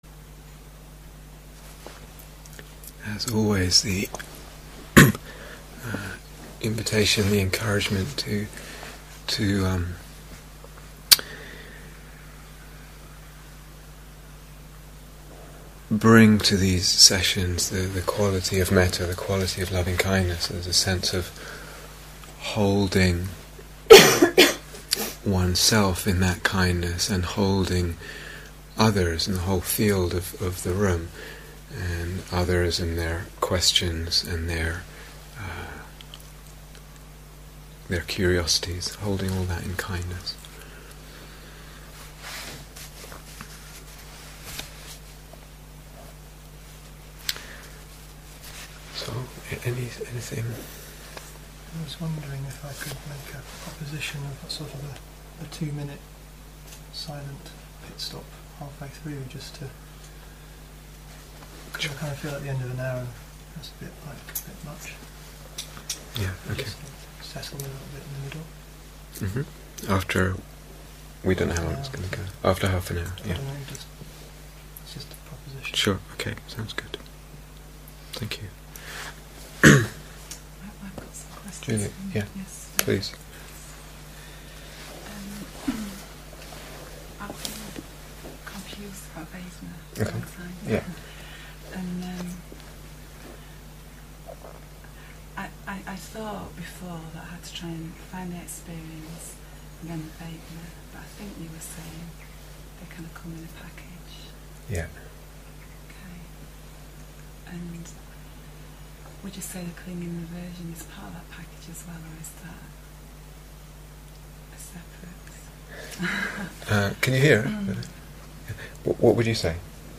Deepening Into Emptiness (Question and Answer Session 4)